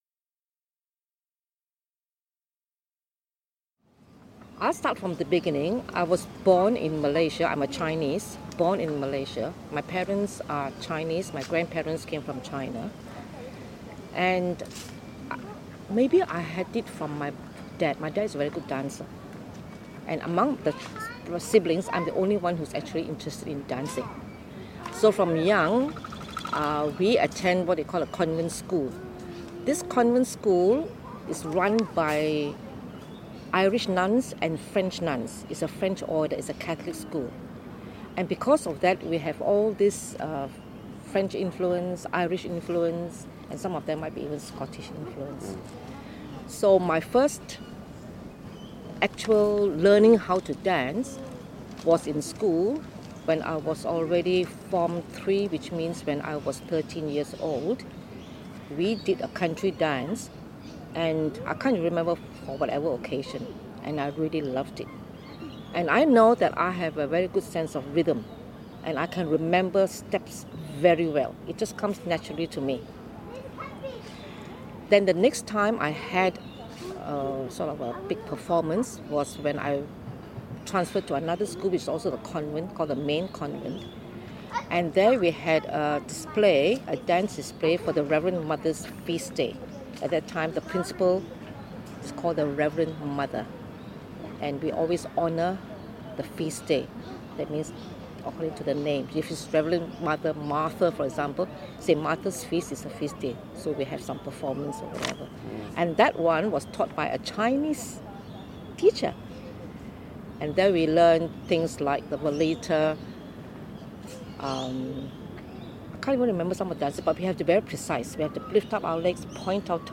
Video Interview